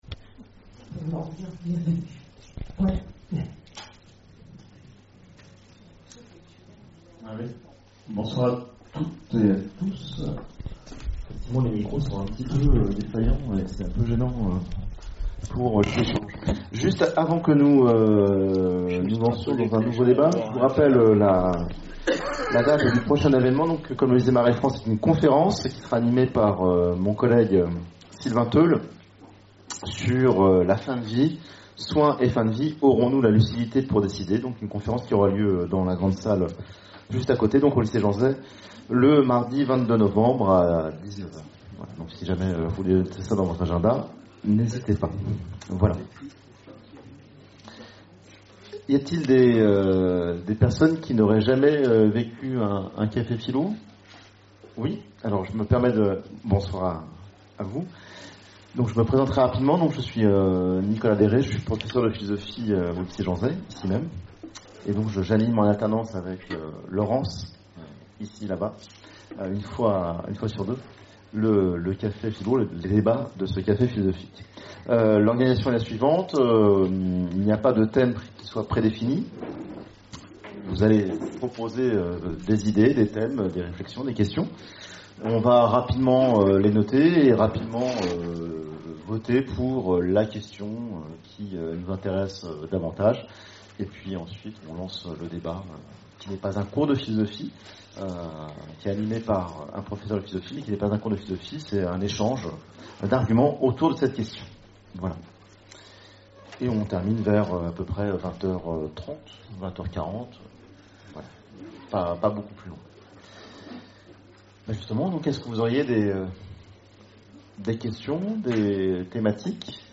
Conférences et cafés-philo, Orléans
CAFÉ-PHILO PHILOMANIA Sobriété et désir peuvent-ils aller de pair ?